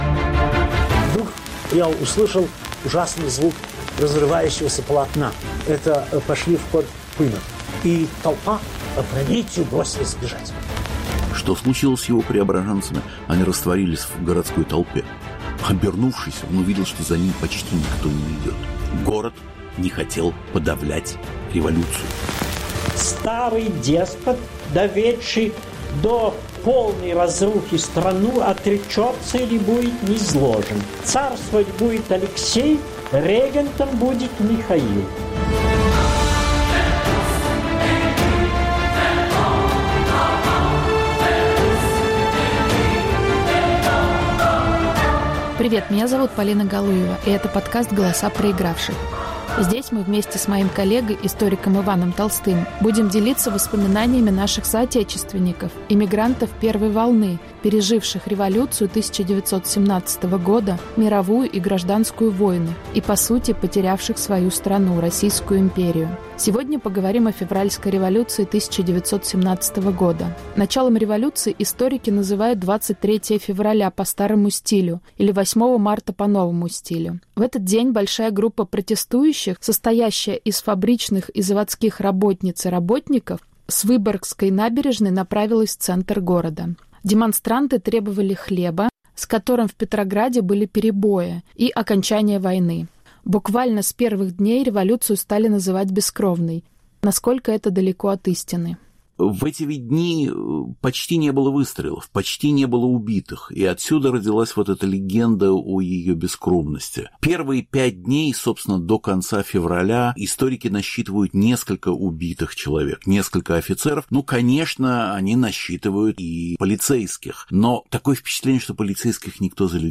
Рассказывают свидетели революционного Петрограда